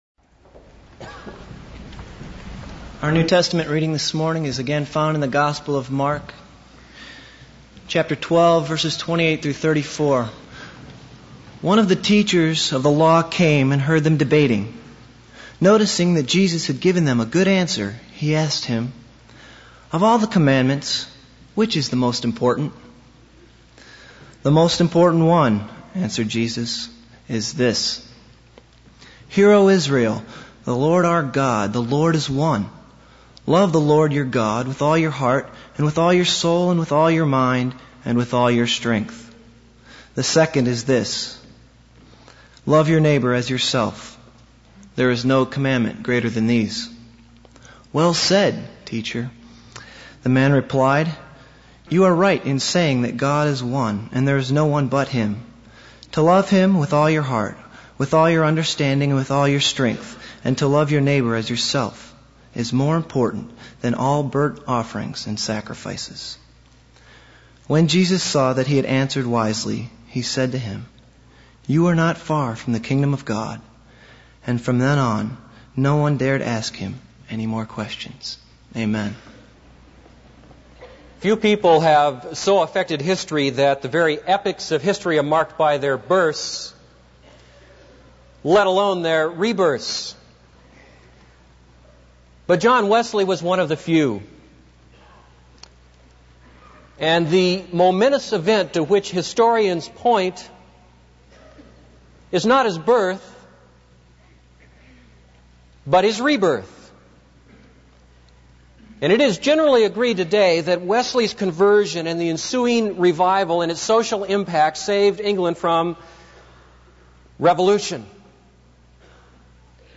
This is a sermon on Mark 12:28-34.